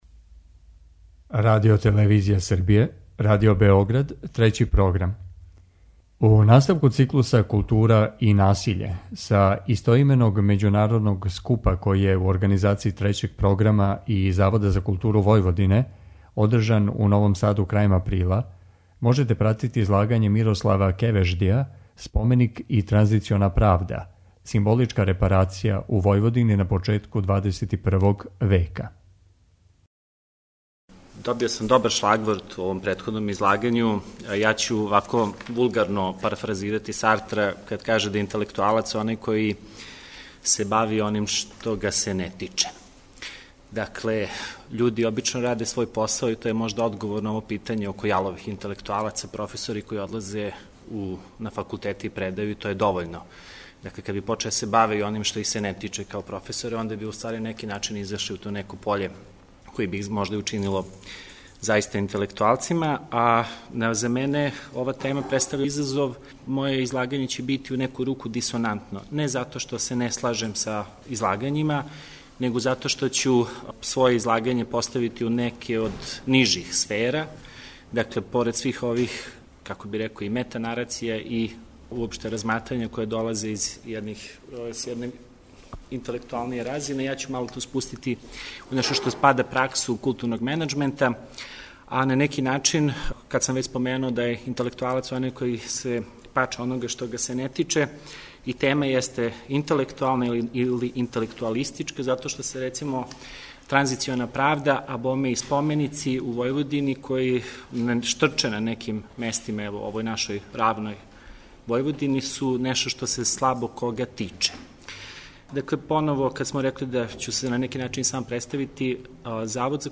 У циклусу КУЛТУРА И НАСИЉЕ, који емитујемо средом, са истоименог научног скупа преносимо излагања предавача и дискусије поводом излагања. Скуп су, у Новом Саду крајем априла, организовали Трећи програм и Завод за културу Војводине.